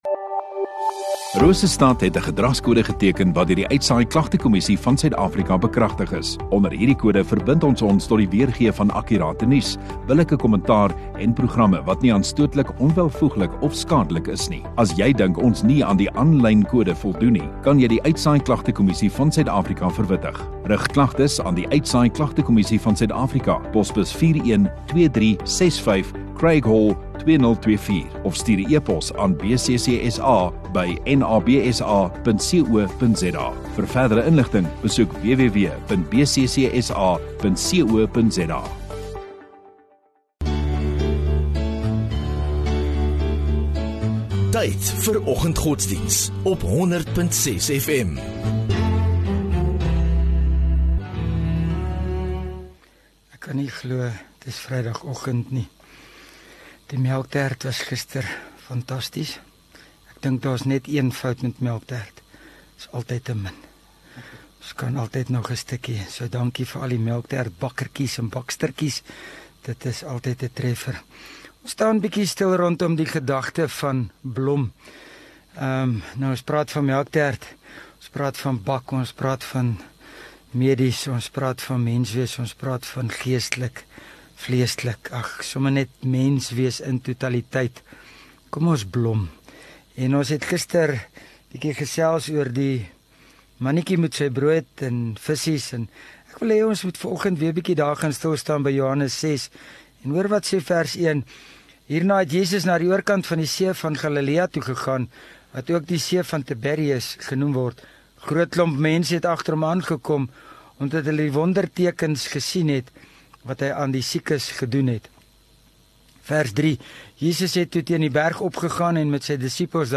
28 Feb Vrydag Oggenddiens